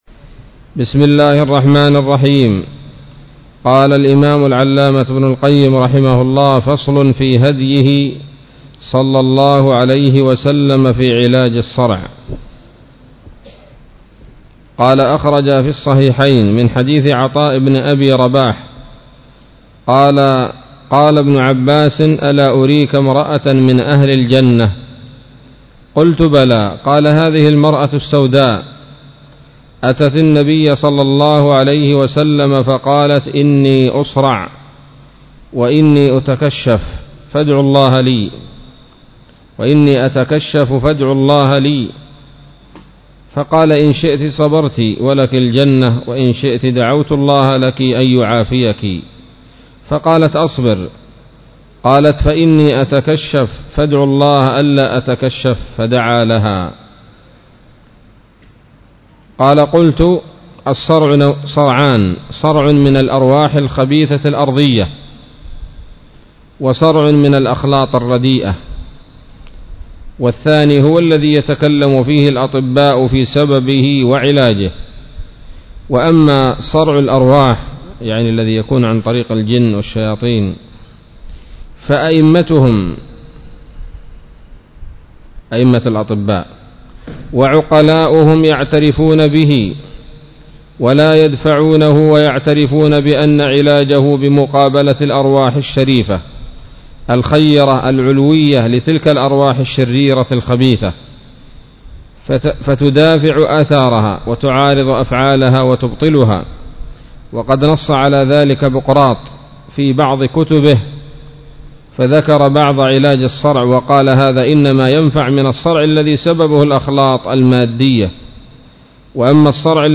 الدرس الثامن عشر من كتاب الطب النبوي لابن القيم